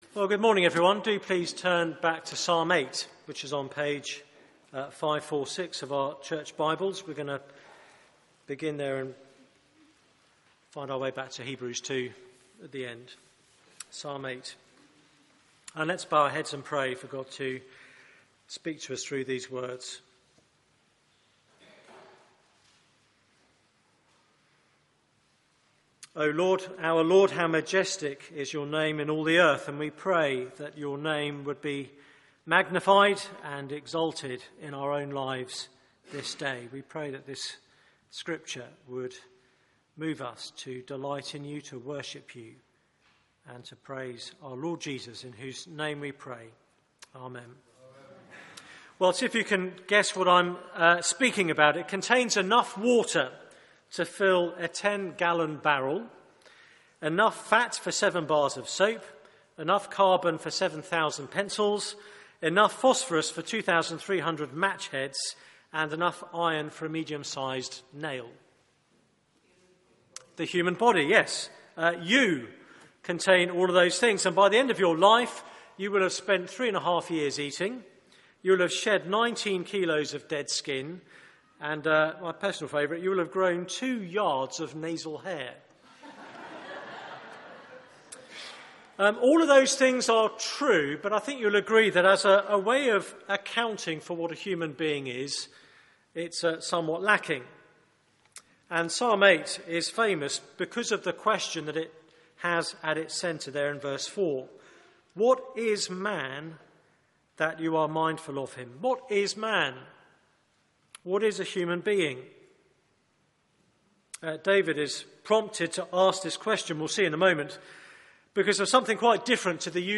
Media for 9:15am Service on Sun 28th Dec 2014 09:15 Speaker
Series: Christmas Psalms Theme: The greatest human being Sermon Search the media library There are recordings here going back several years.